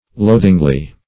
loathingly - definition of loathingly - synonyms, pronunciation, spelling from Free Dictionary Search Result for " loathingly" : The Collaborative International Dictionary of English v.0.48: Loathingly \Loath"ing*ly\, adv.